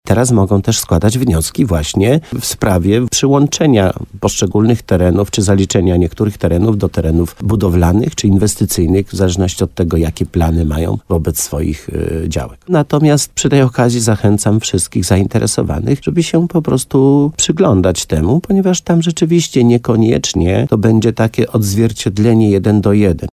– Jeszcze tylko przez kilka dni mieszkańcy mogą zgłaszać swoje wnioski – mówił w programie Słowo za Słowo na antenie RDN Nowy Sącz, wójt gminy Korzenna, Leszek Skowron.